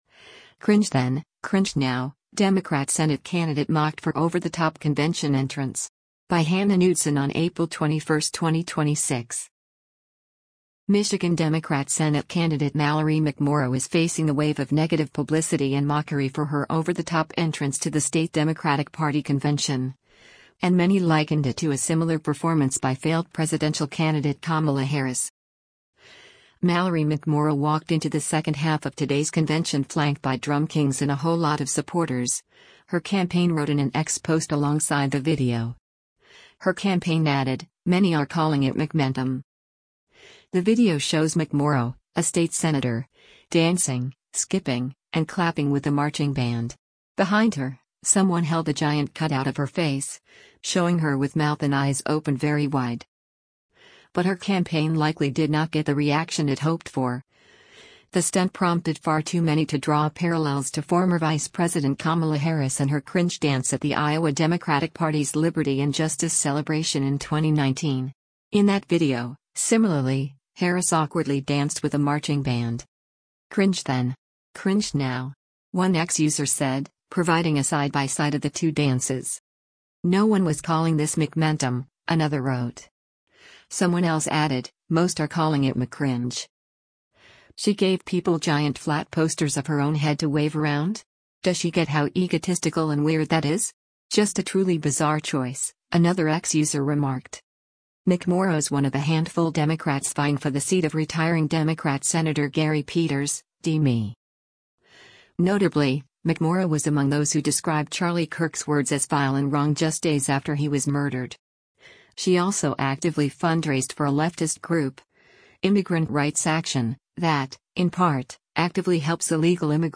“Mallory McMorrow walked into the second half of today’s convention flanked by DrumKINGZ and a whole lot of supporters,” her campaign wrote in an X post alongside the video.
The video shows McMorrow – a state senator – dancing, skipping, and clapping with the marching band.